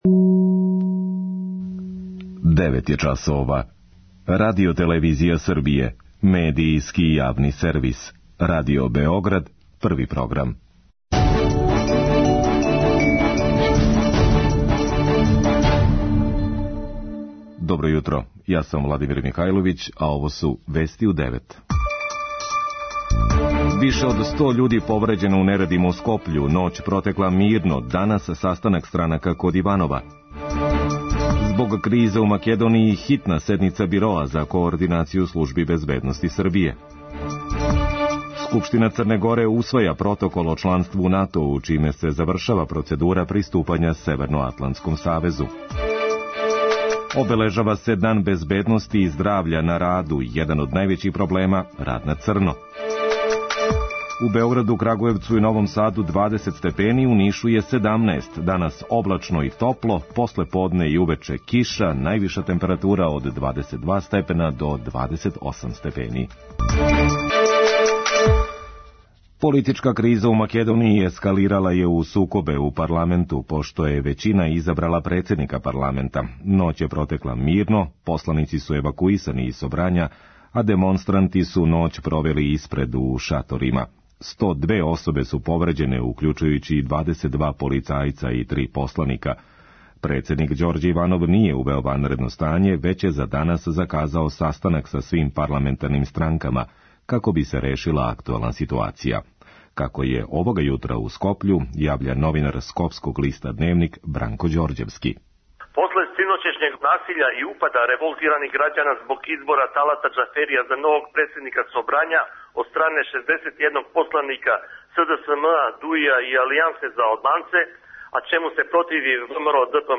преузми : 3.96 MB Вести у 9 Autor: разни аутори Преглед најважнијиx информација из земље из света.